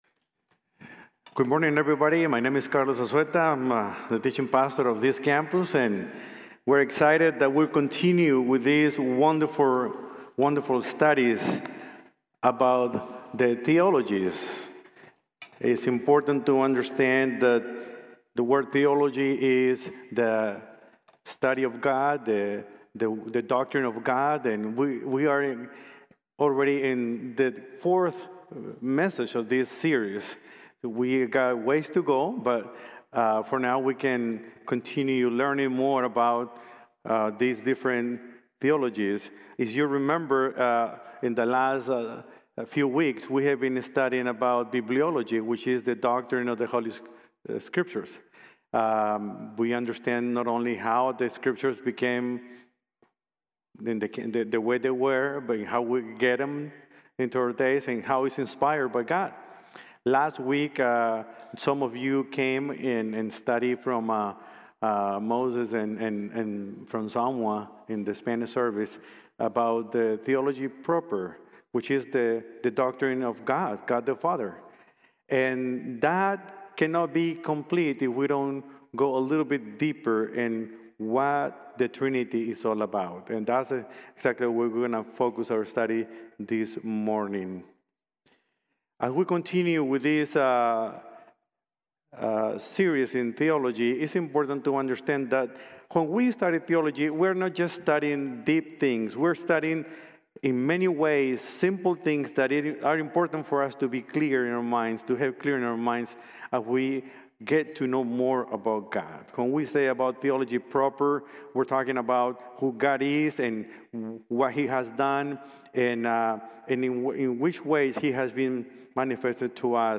The Trinity | Sermon | Grace Bible Church